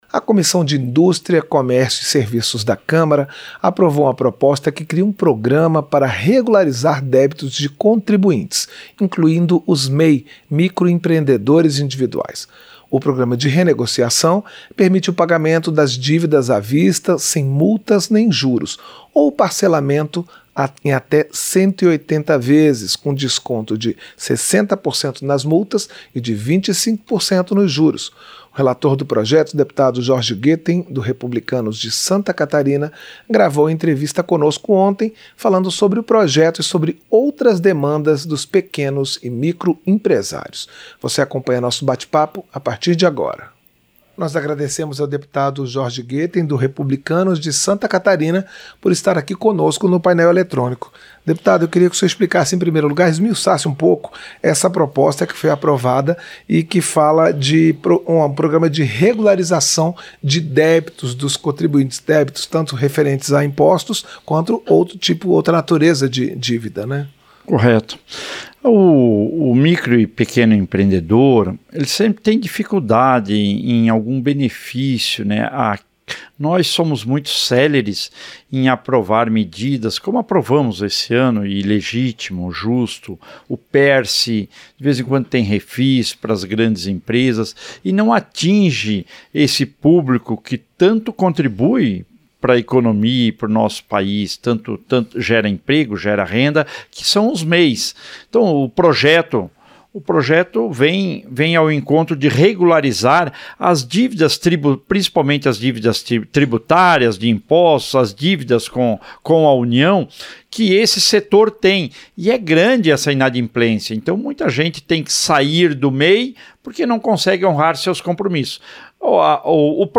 Entrevista - Dep. Jorge Goetten (Rep-SC)